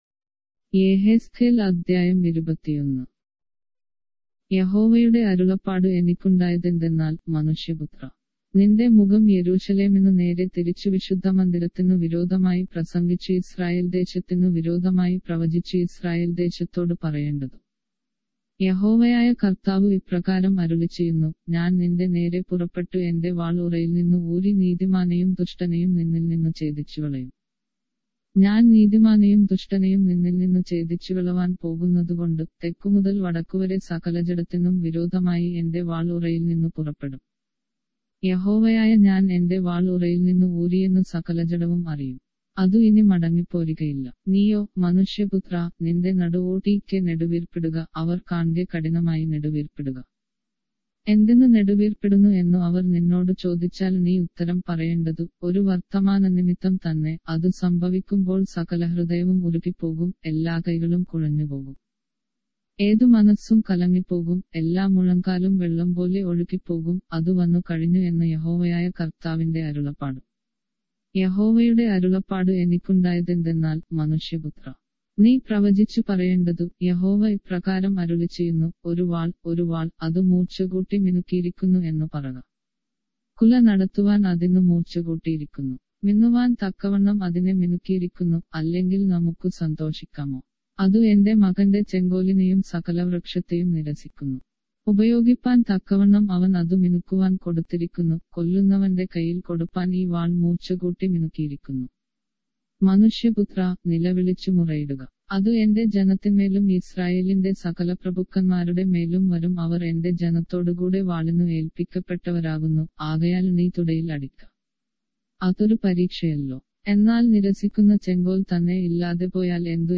Malayalam Audio Bible - Ezekiel 21 in Ervpa bible version